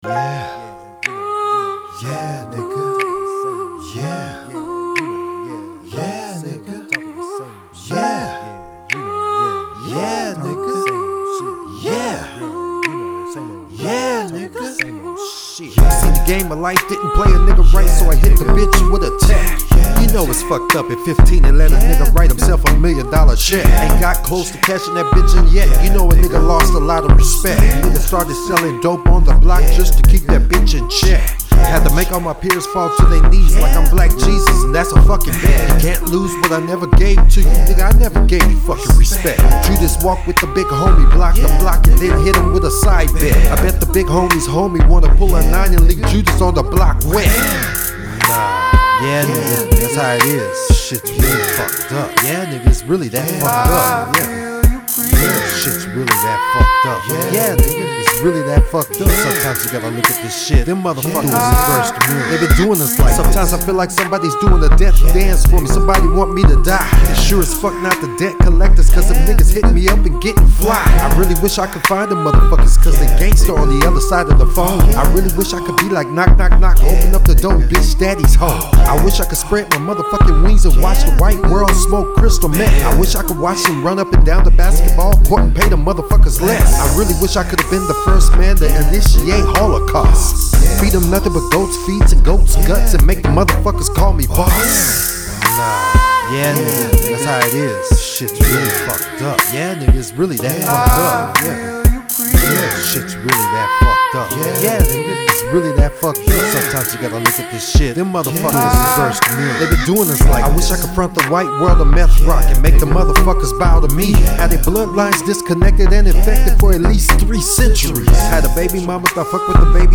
DARK RAP RAP